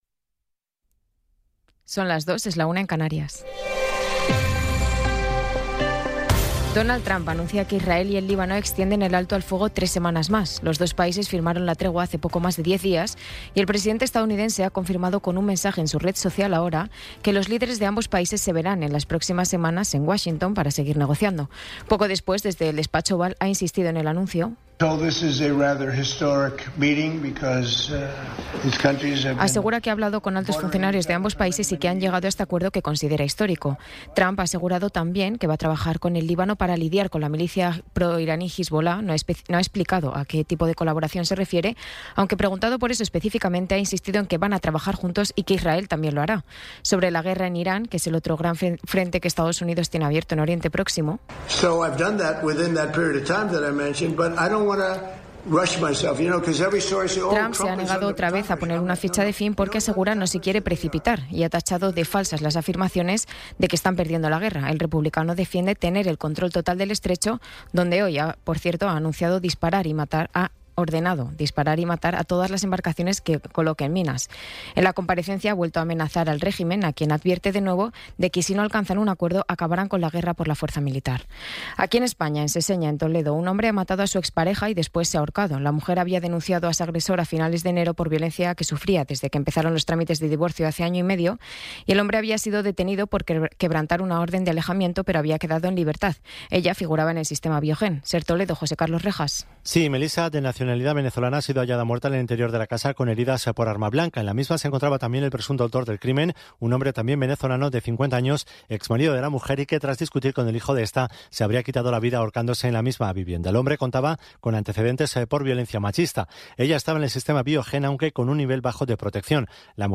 Resumen informativo con las noticias más destacadas del 24 de abril de 2026 a las dos de la mañana.